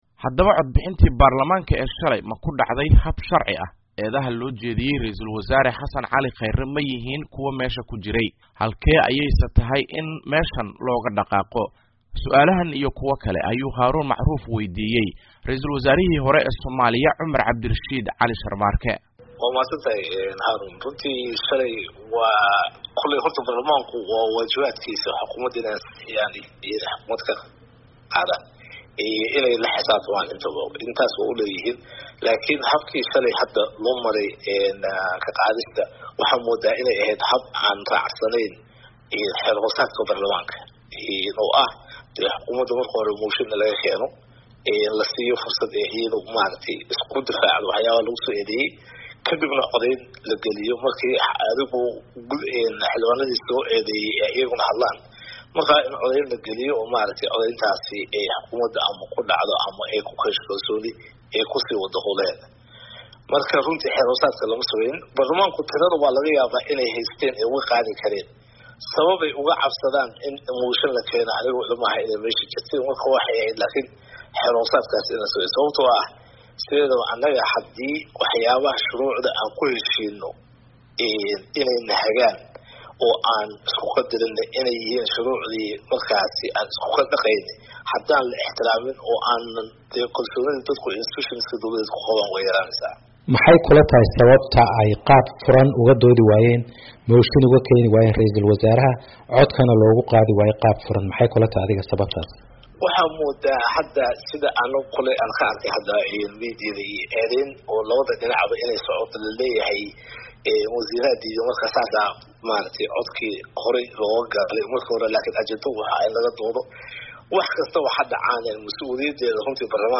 Dhagayso: Senator Cumar Cabdirashiid oo kahadlay xil ka qaadista Khayre, eedaha loo jeediyey iyo doorashada
Ra’iisul Wasaarihi hore ee Soomaaliya ilsa markaasna ah senator ka tirsan Aqalka Sare Cumar Cabdilrashiid Cali Sharmarke ayaa idaacadda VOA ka waraysatay suaalaha ku hareeraysan codbixintii Golaha Shacabka ee shalay inay ku dhacday hab sharci ah.
Dhagayso waraysiga VOA ka qaaday Senator Cumar